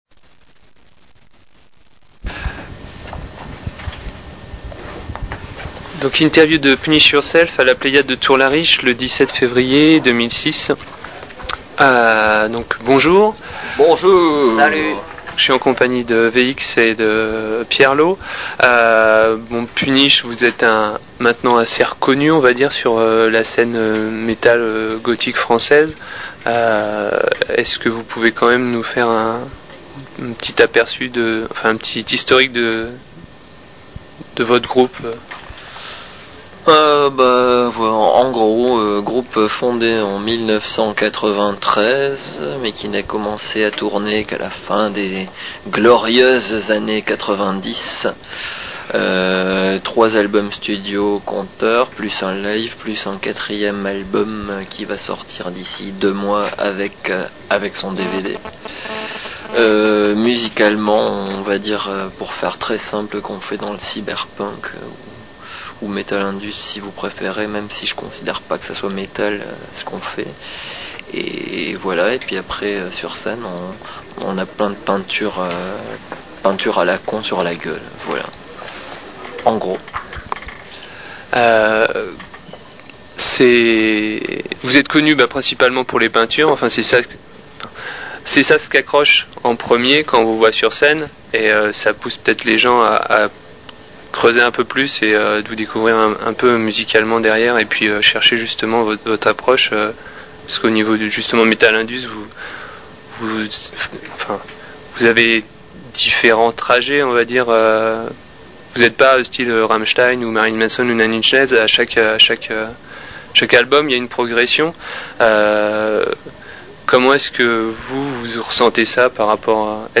Interview du groupe Punish Yourself, enregistrée le 17.02.2006